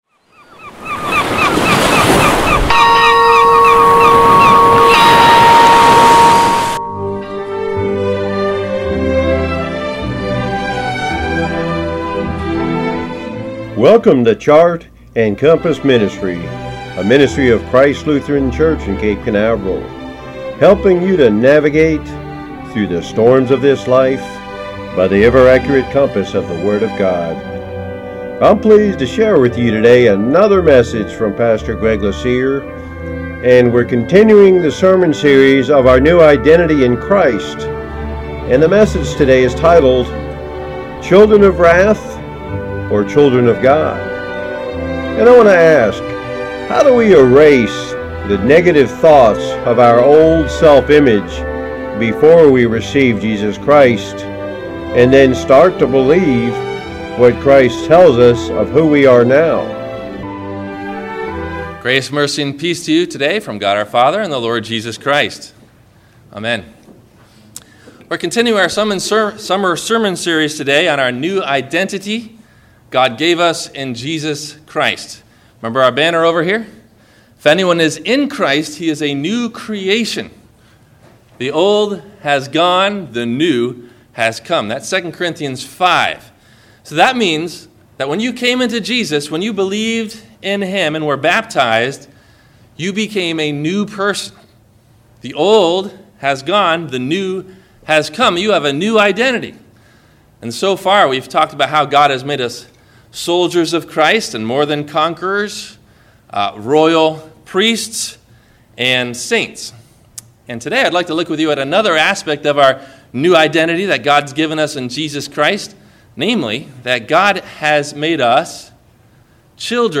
Children of Wrath or Children of God? – WMIE Radio Sermon – July 13 2020